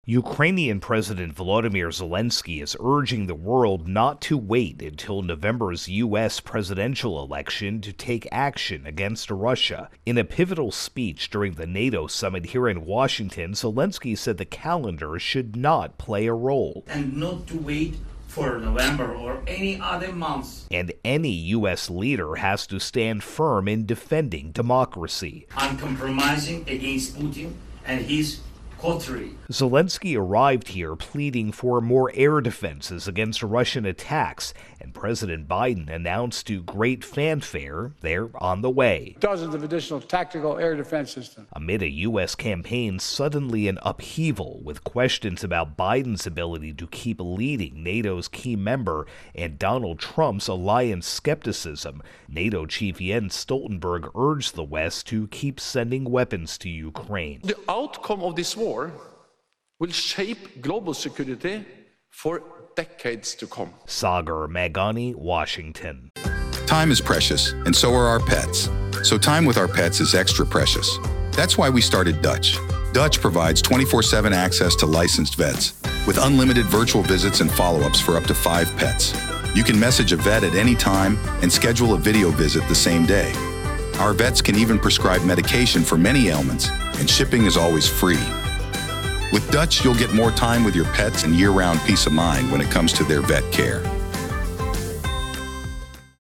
AP Washington correspondent reports Ukrainian President Volodmyr Zelenskyy is urging the world not to wait until November's presidential election in the U.S. to take action against Russia.